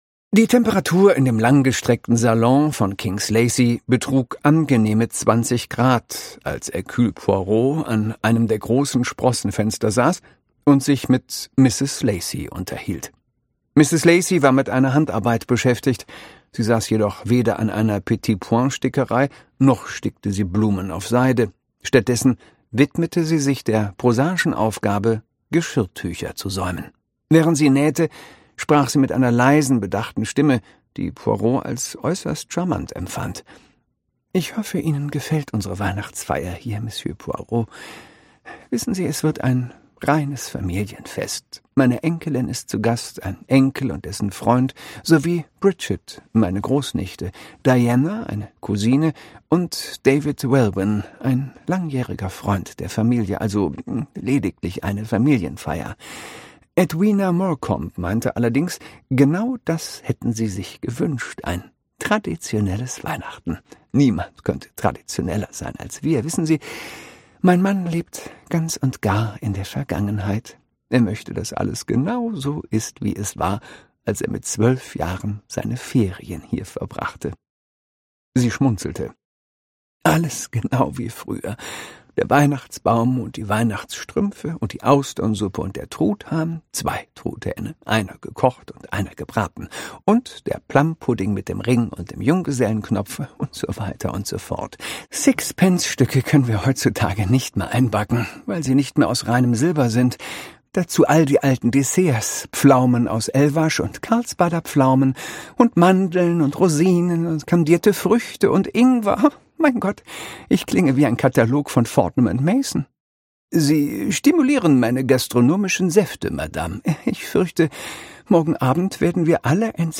Christoph Maria Herbst (Sprecher)
Ungekürzte Lesung